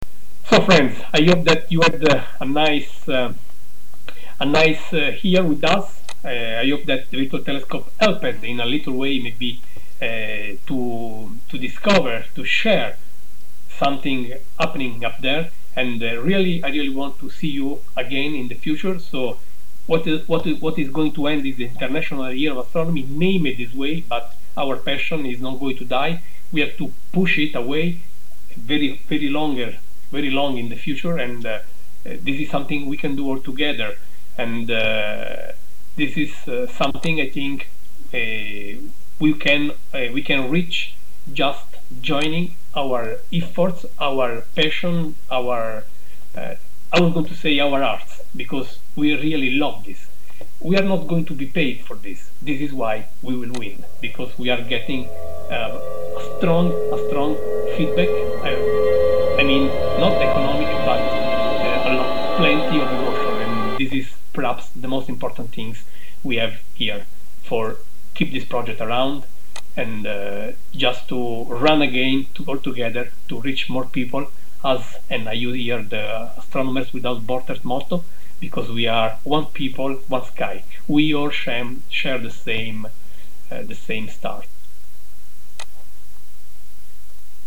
IYA2009 ONLINE-CLOSING-EVENT of Virtualtelescope.